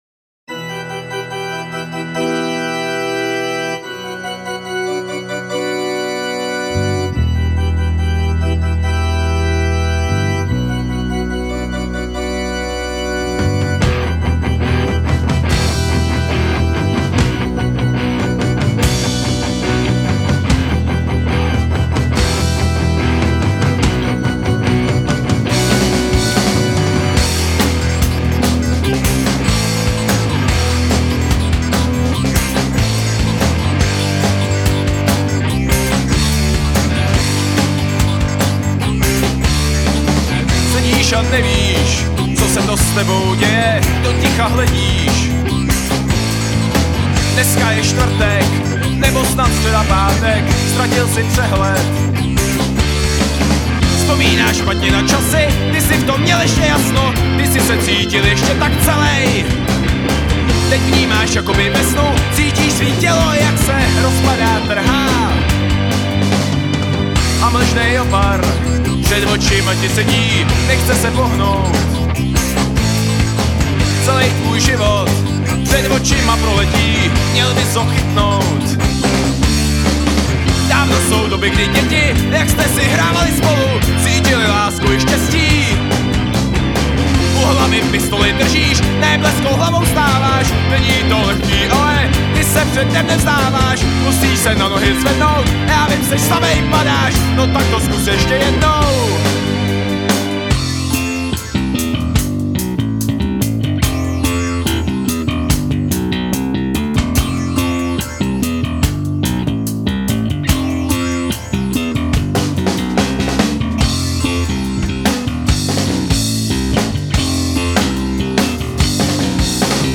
Natočeno v září 2003 v digitálním studiu PyHa v Plzni.